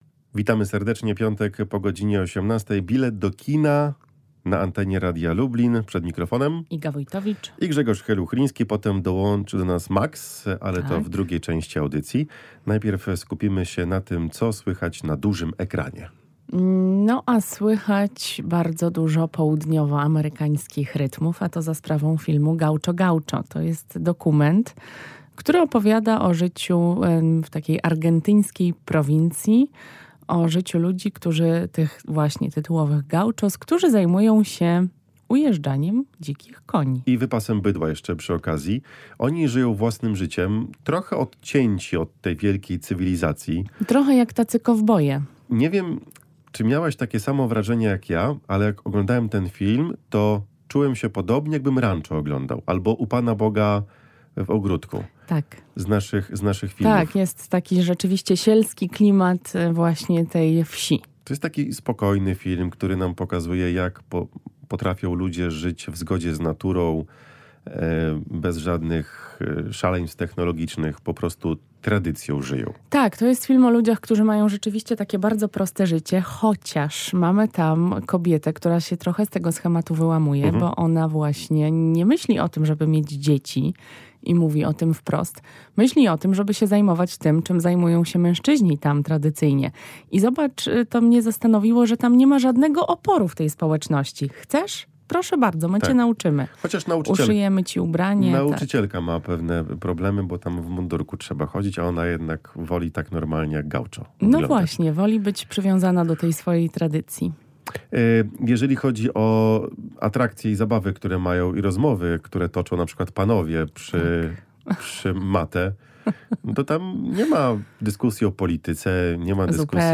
W dzisiejszej audycji rozmawiamy o niezwykłym dokumencie „Gaucho Gaucho”, dzięki któremu poznamy życie niewielkiej argentyńskiej społeczności. Na platformach trzy propozycje – film „Czarna Wdowa”, i dwa seriale – „Syreny” i „Przyjaciele i sąsiedzi”.